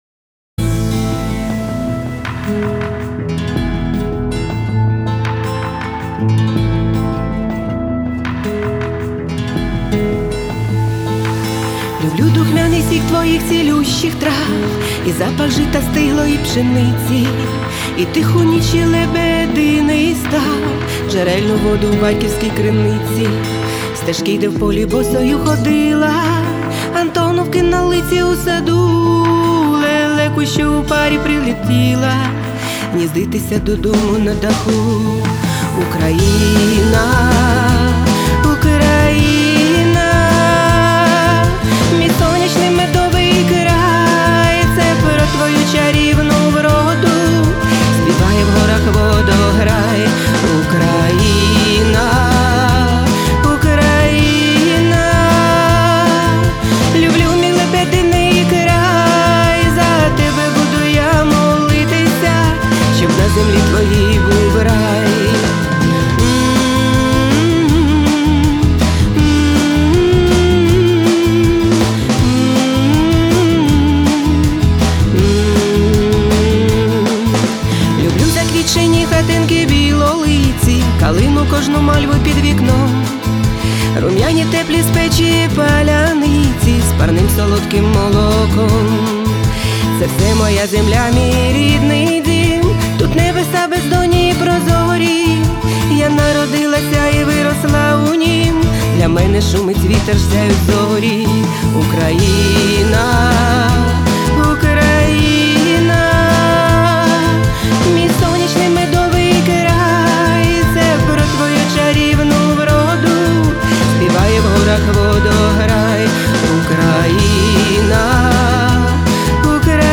Рубрика: Поезія, Авторська пісня
Гарна мелодія! Приємне динамічне аранжування!